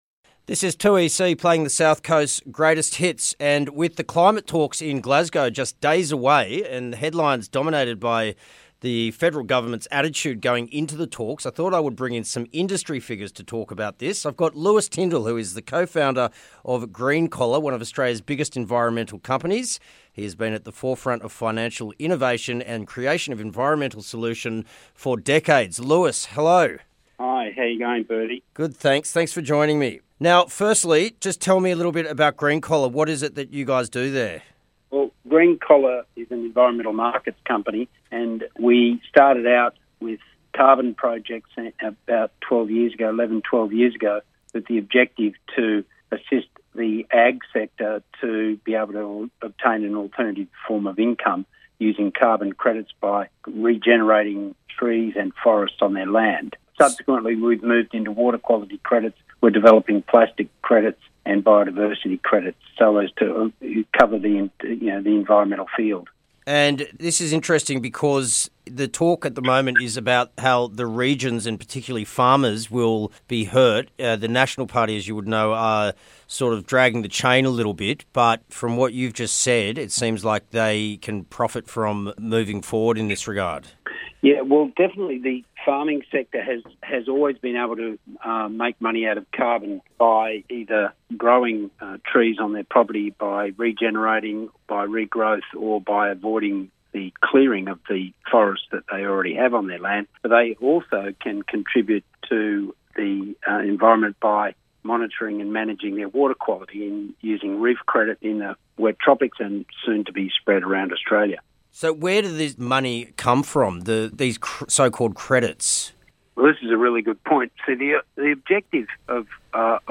With so many different opinions clouding the space, it's time to speak to an expert.